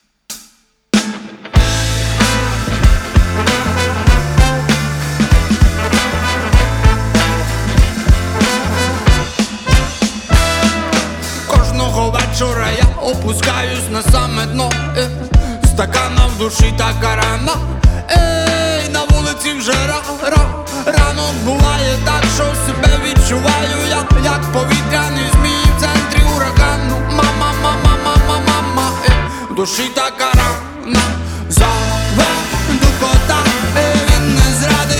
Жанр: Фолк-рок / Альтернатива / Украинские
# Alternative Folk